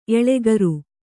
♪ eḷegaru